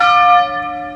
Lançons le programme, la LED s'allume en permanence et la cloche tinte toutes les cinq secondes.
A chaque déplacement de la cloche correspondra un son de cloche.